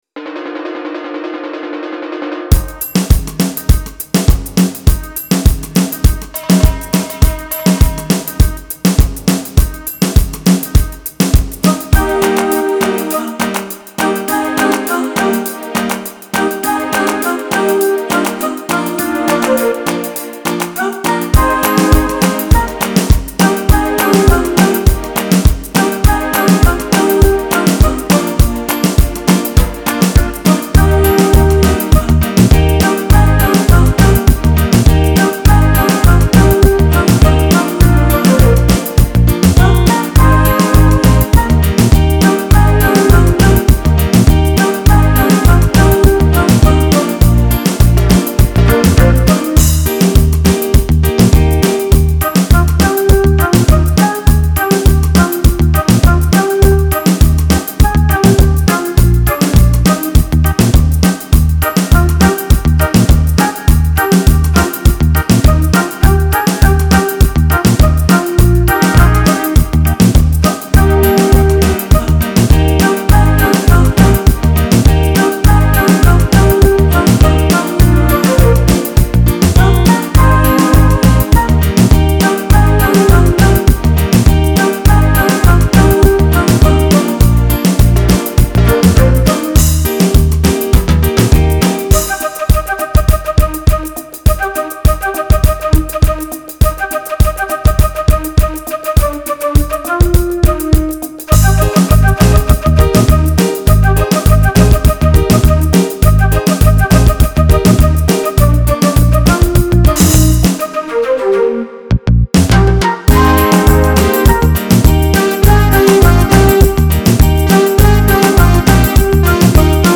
The beat is unusual – almost a fast reggae but not quite.